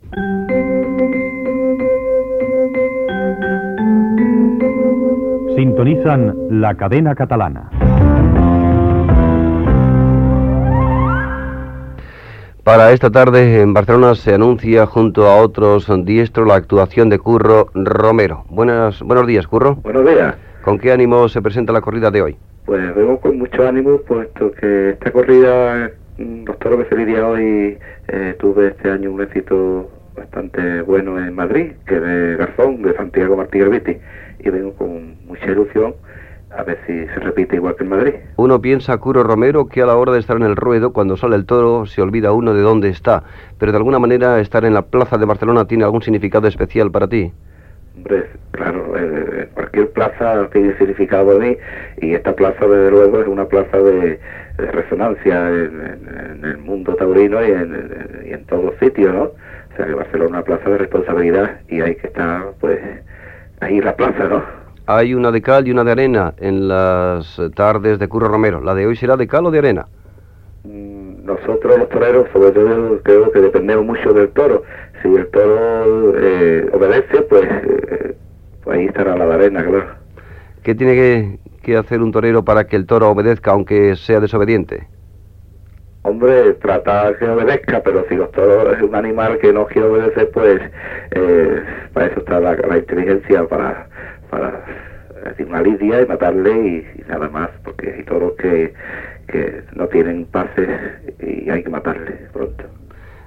Indicatiu de l'emissora i entrevista al torero Curro Romero que toreja aquella tarda a la plaça Monumental de Barcelona